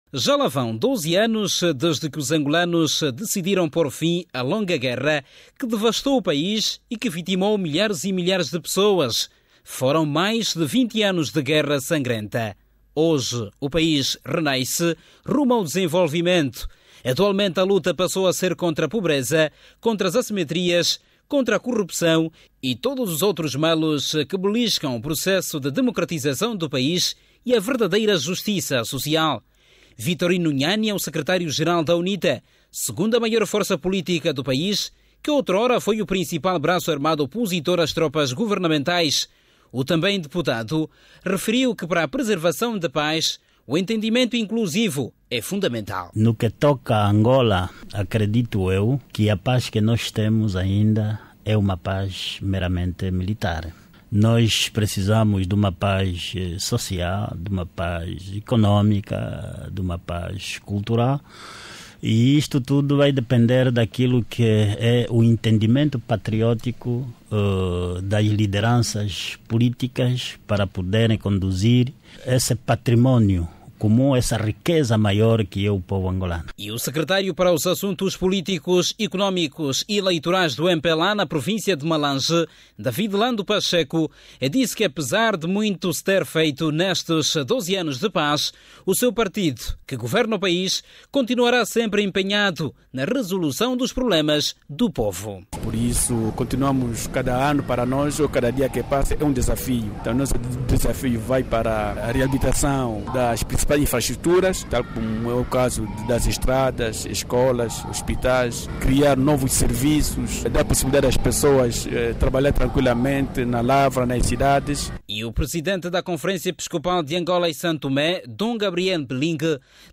Vitorino Nhany é o secretário–geral da UNITA, segunda maior força politica do país que outrora foi o principal braço armado opositor às tropas governamentais, o também deputado referiu que para a preservação da paz o entendimento inclusivo é fundamental.
E o presidente da CEAST Dom Gabriel Mbilingue lamentou o facto de existirem ainda no seio de alguns líderes políticos sinais claros de mentes minadas pelos traumas da guerra.